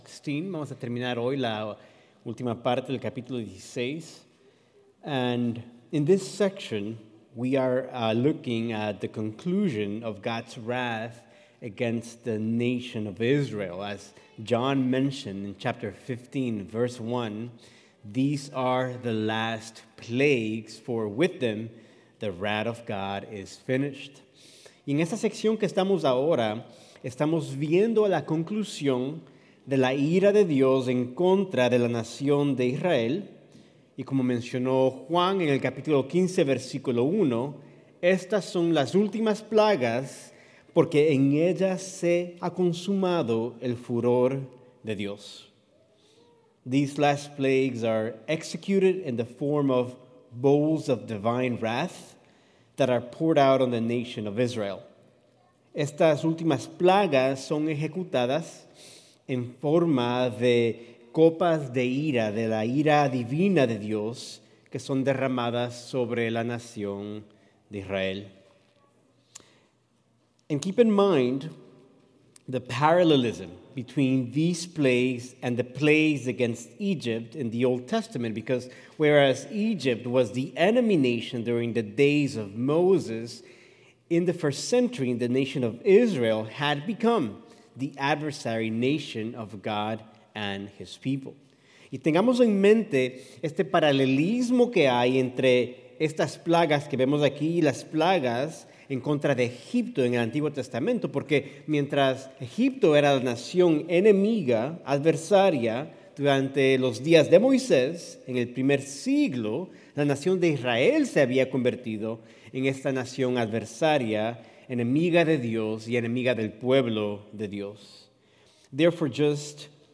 Sermons | Emmanuel Bible Church